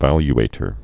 (văly-ātər)